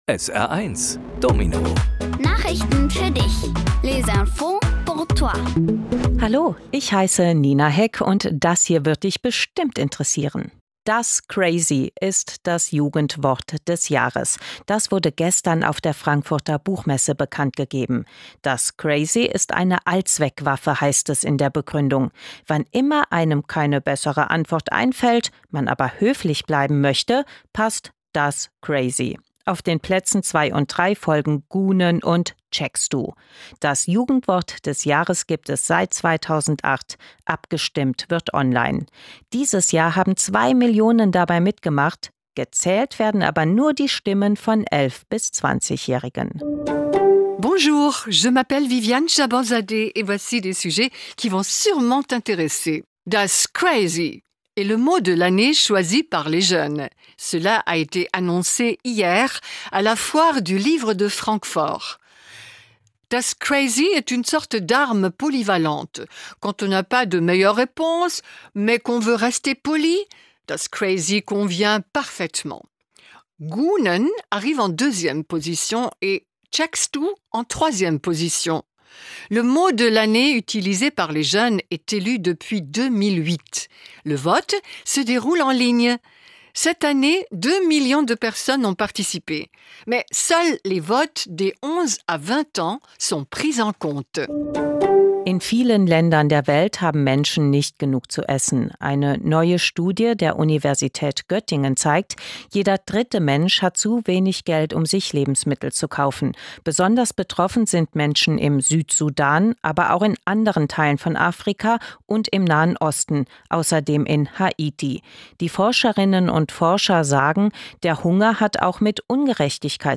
Die wichtigsten Nachrichten der Woche kindgerecht aufbereitet auf Deutsch und Französisch